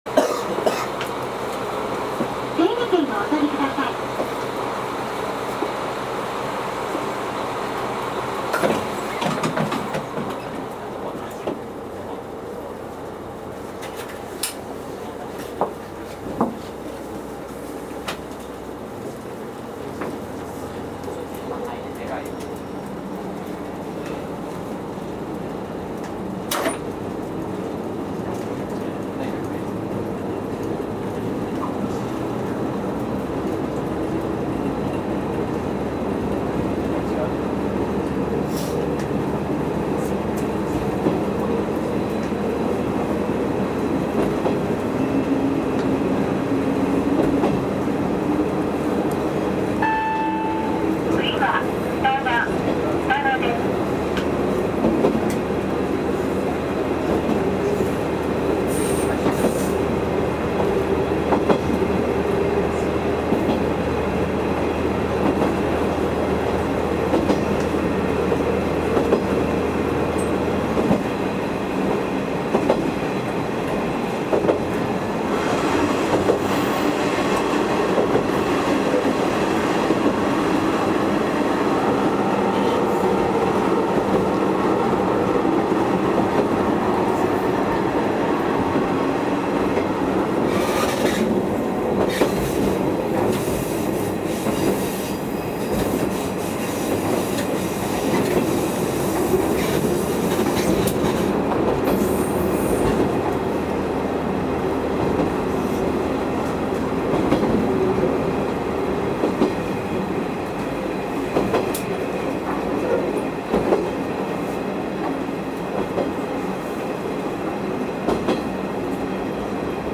走行音
録音区間：大内～二名(お持ち帰り)